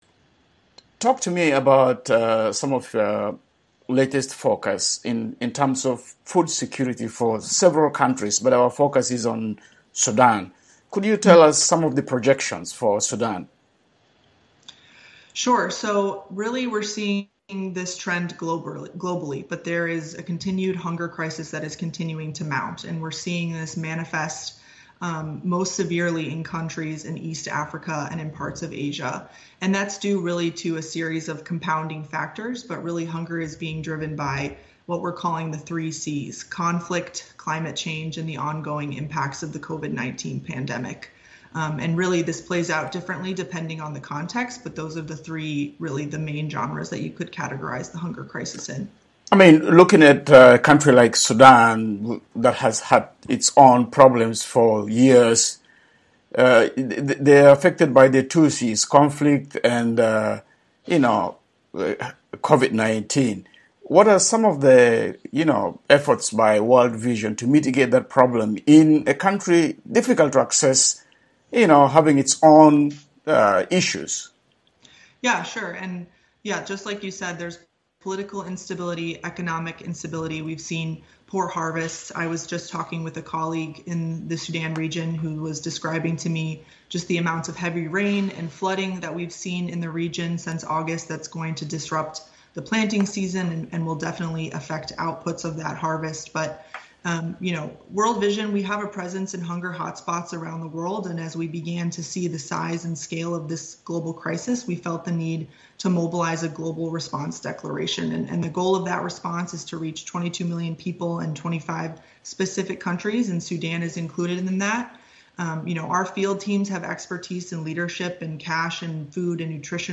The Interview was edited for brevity and clarity.